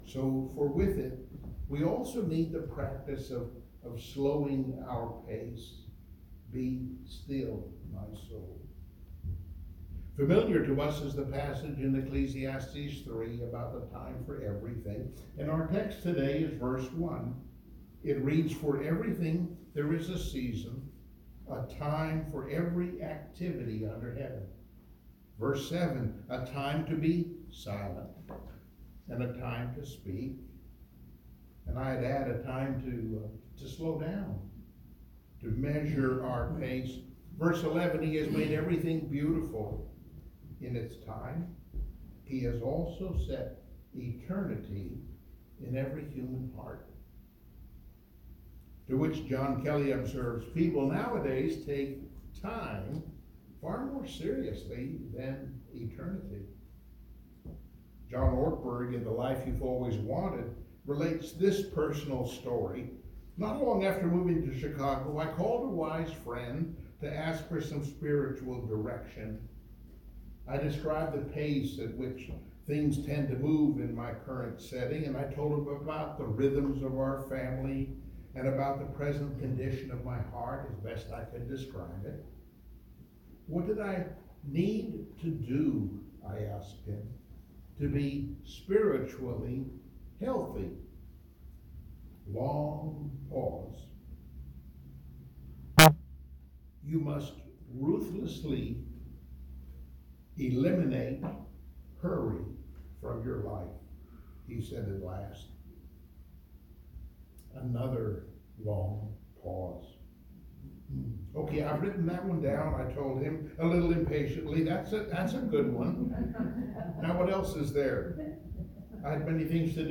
Service Type: Sunday Morning Worship Download Files Notes Bulletin Topics: folly of convenience , God's time , patience « ”Silence and Solitude” ”Who Is Our God?”